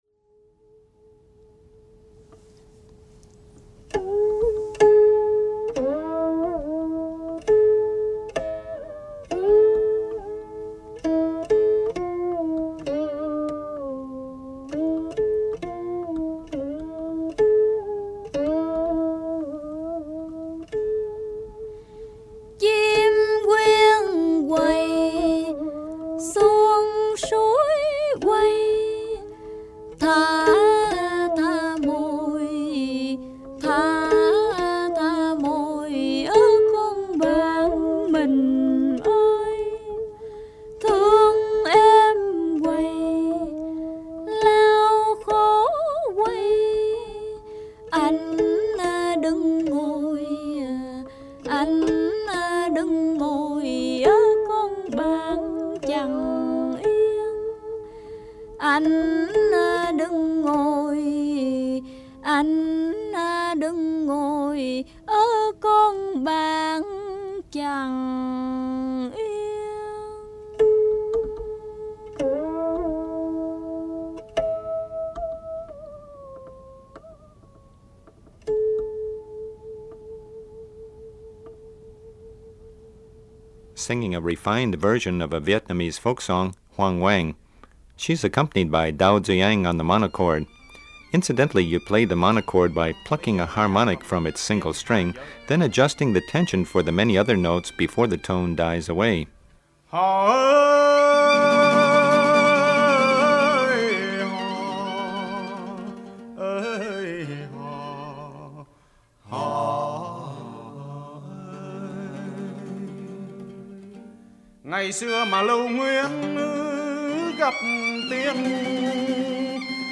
VIETNAMESE   Traditional Vietnamese opera; dan bao monochord; & a comedy music act in California
Vietnamese.mp3